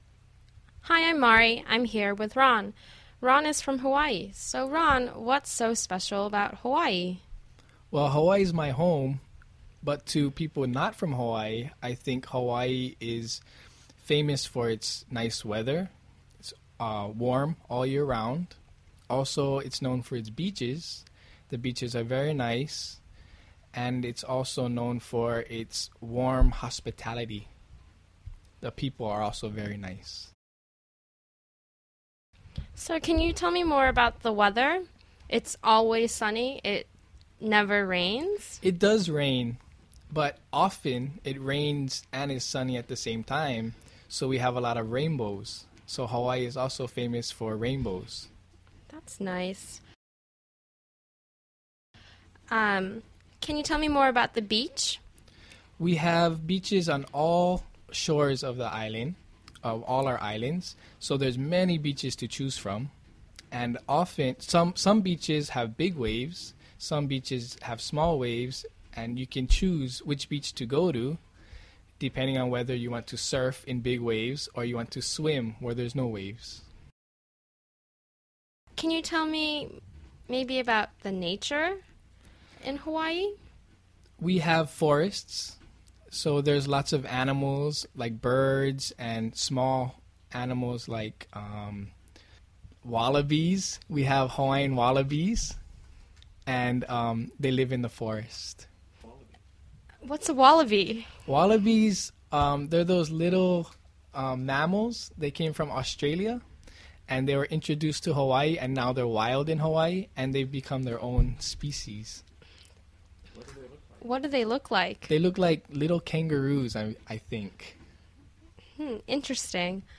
英语初级口语对话正常语速11：夏威夷（mp3+lrc）